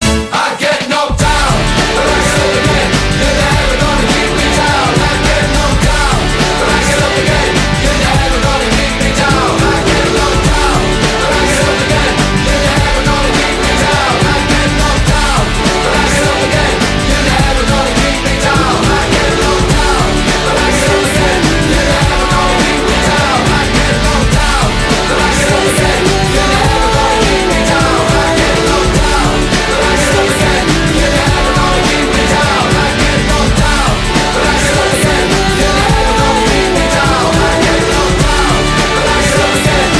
dance-punk
панк-рок
dance rock